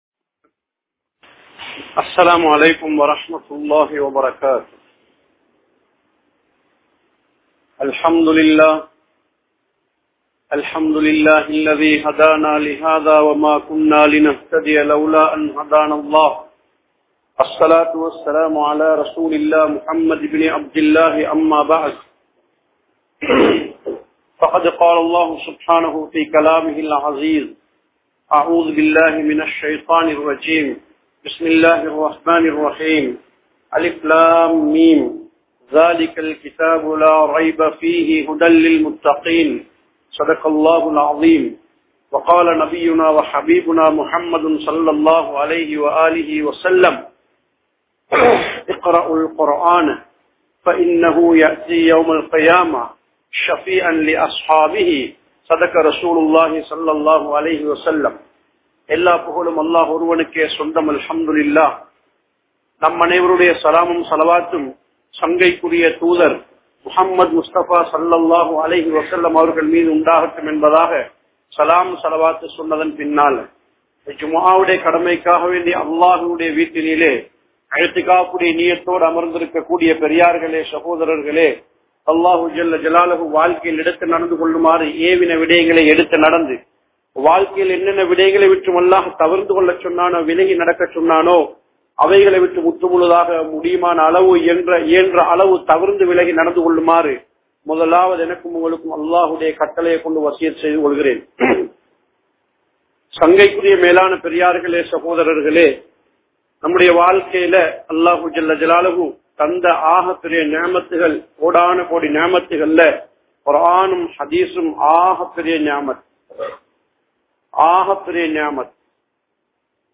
Al-Quranuku Ethiraaha Ungalaal Eppadi Pease Mudium | Audio Bayans | All Ceylon Muslim Youth Community | Addalaichenai
Al Azhar Jumua Masjith